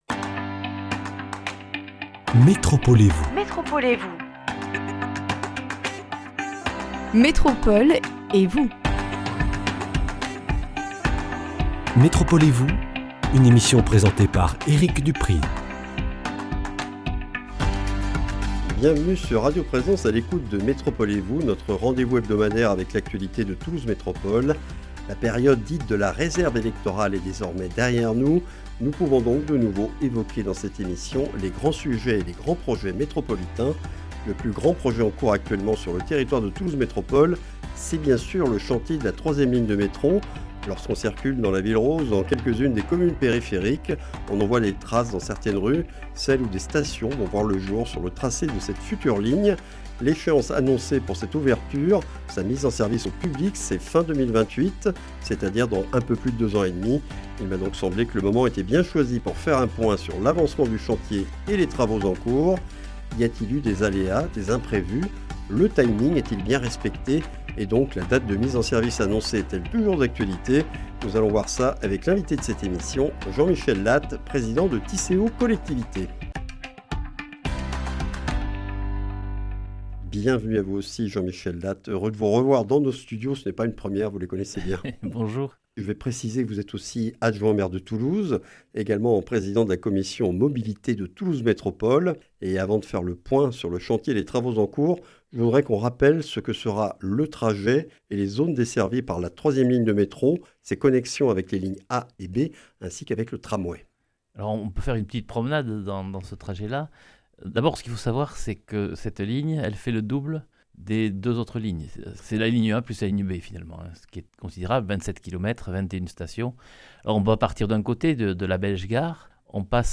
Nous faisons un point sur l’avancement du chantier de la 3e ligne du métro toulousain, future Ligne C, avec Jean-Michel Lattes, adjoint au maire de Toulouse, président de la commission Mobilités de Toulouse Métropole, président de Tisséo Collectivités.